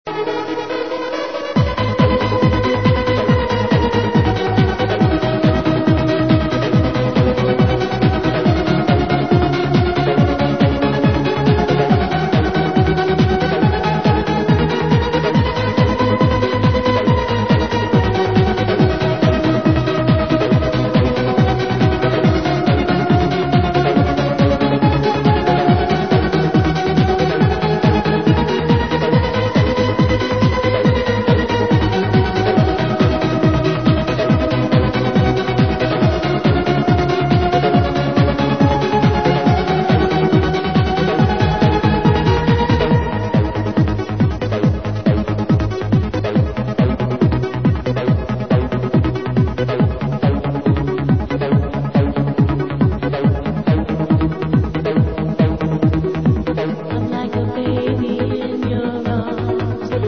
Активная, бодрящая музыка для динамической медитации.